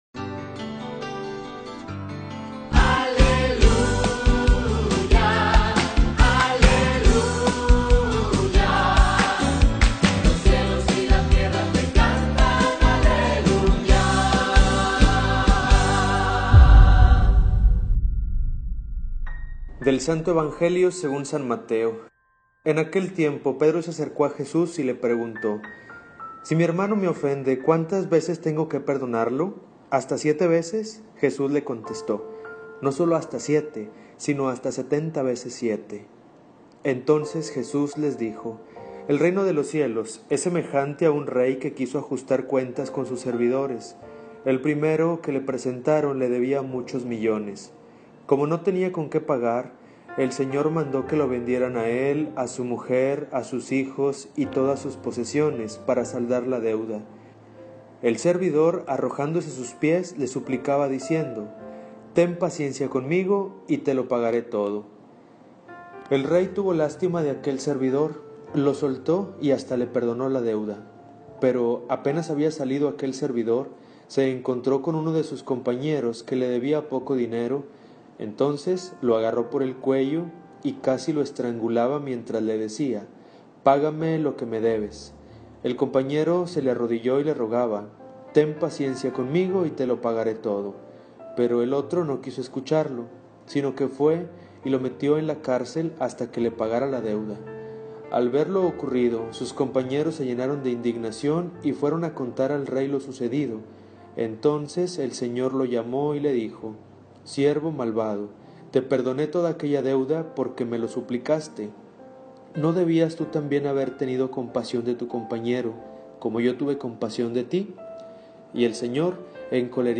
homilia_ordinario_XXIV_Perdonar_de_corazon.mp3